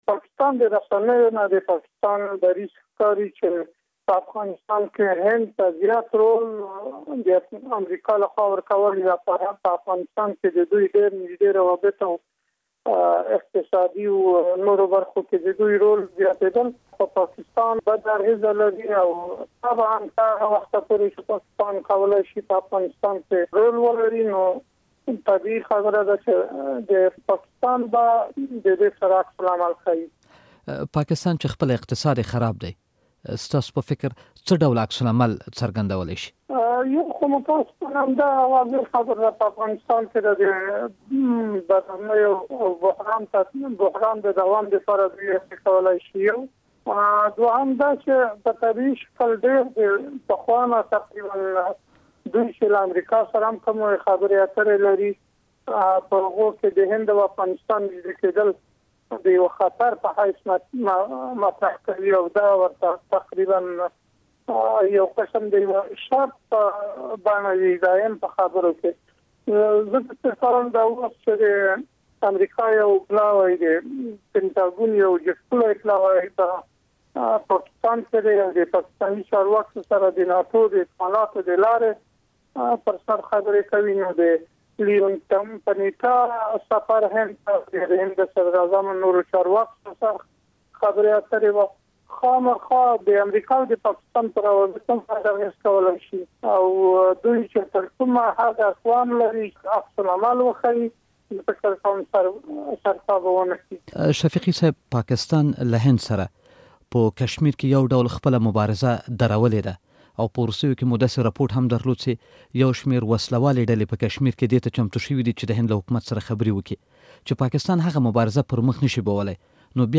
هند ته د امریکا د دفاع وزیر سفر په هکله مرکه